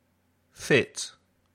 Pronunciación
The following sounds often cause problems for Spanish speakers. Listen and choose the word you hear.
/i:/ - feet               /I/ - fit